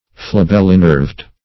Search Result for " flabellinerved" : The Collaborative International Dictionary of English v.0.48: Flabellinerved \Fla*bel"li*nerved`\, a. [L. flabellum a fan + E. nerve.]